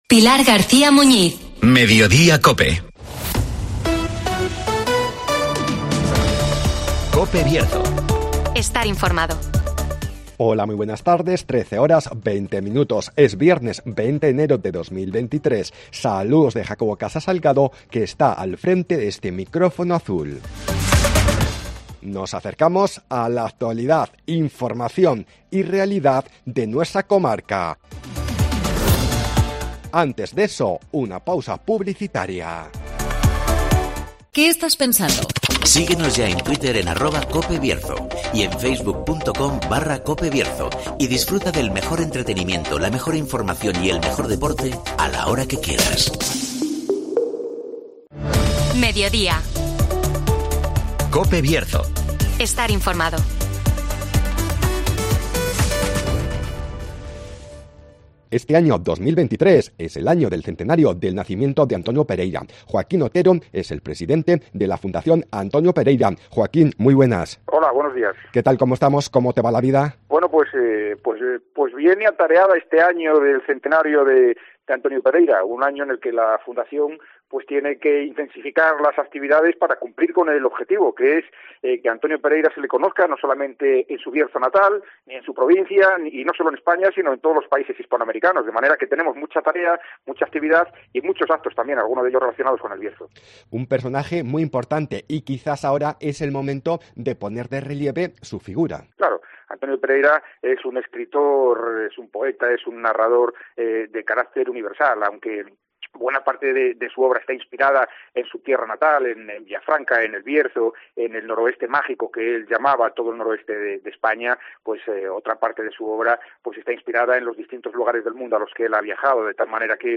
En marzo arrancarán los actos del centenario del nacimiento de Antonio Pereira (Entrevista a Joaquín Otero)